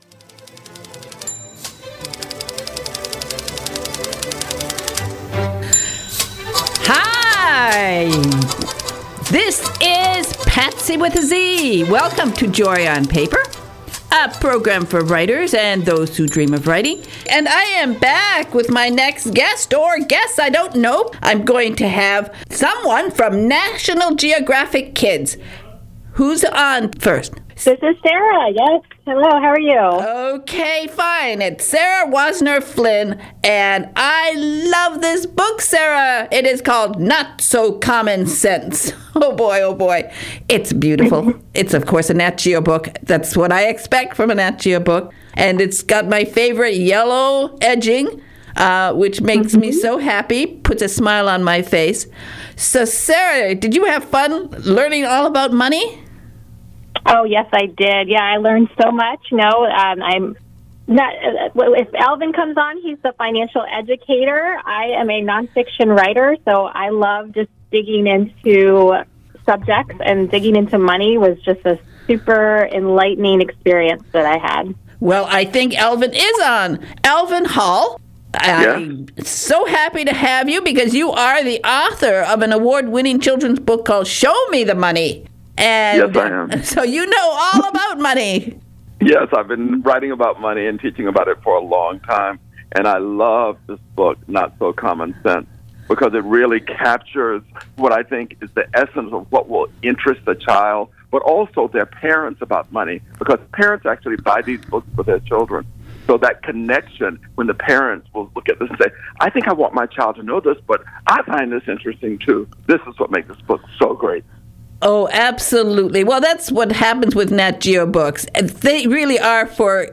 So smart and he has a great voice.